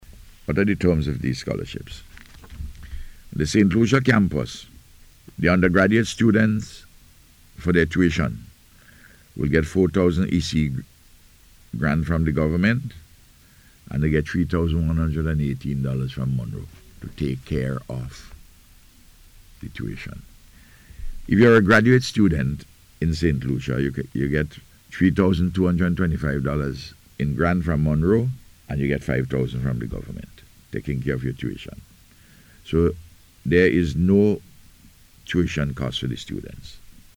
Prime Minister and Minister responsible for Tertiary Education, Dr. Ralph Gonsalves made the announcement on NBC Radio’s Face to Face Programme this morning.